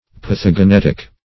Pathogenetic \Path`o*ge*net"ic\, a.